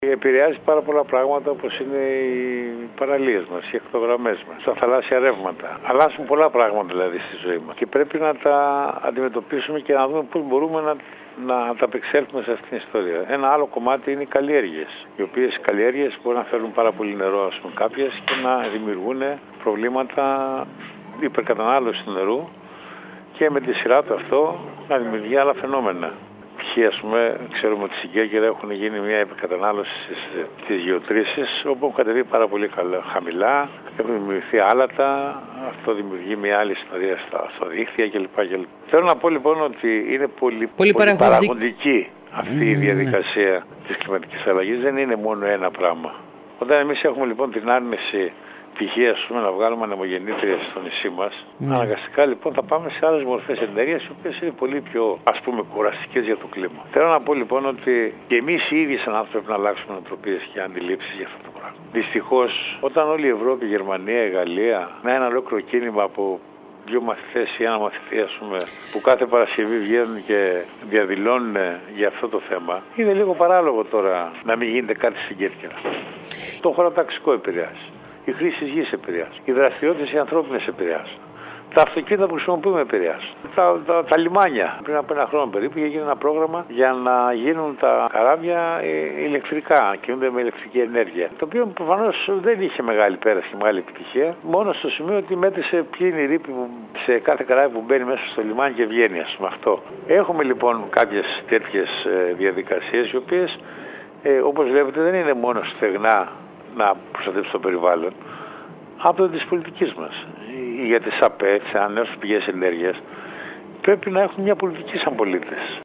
Μιλώντας σήμερα στην ΕΡΤ Κέρκυρας ο αντιπεριφερειάρχης περιβάλλοντος Σάκης Τσούκας επεσήμανε ότι υπάρχουν πολλά περιθώρια άσκησης πολιτικών προστασίας περιβάλλοντος σε τοπικό επίπεδο. Έφερε πολλά παραδείγματα που οδηγούν σε πολιτικές που μπορούν να βελτιώσουν την κατάσταση.